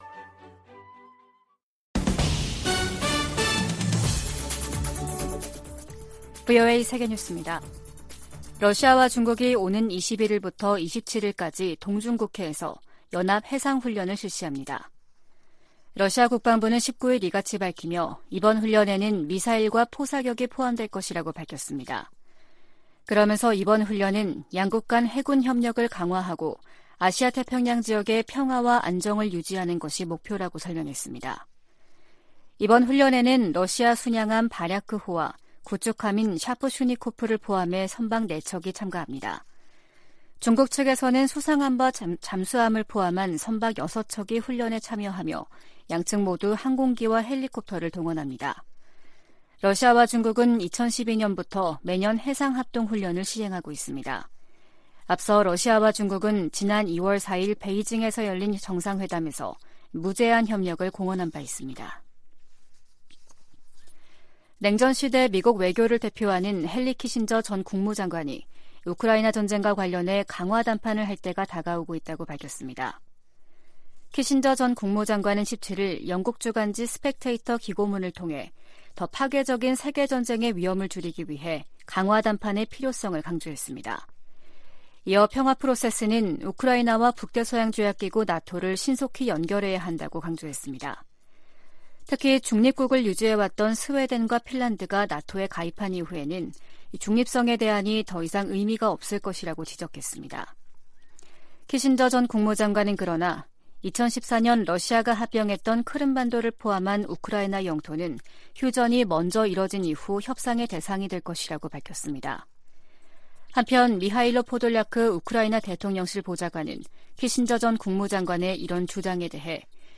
VOA 한국어 아침 뉴스 프로그램 '워싱턴 뉴스 광장' 2022년 12월 20일 방송입니다. 북한은 내년 4월 군 정찰위성 1호기를 준비하겠다고 밝혔지만 전문가들은 북한의 기술 수준에 의문을 제기하고 있습니다. 미국 국무부는 북한이 고출력 고체 엔진실험을 감행한 데 대해 국제사회가 북한에 책임을 묻는 일을 도와야 한다며 단합된 대응을 강조했습니다.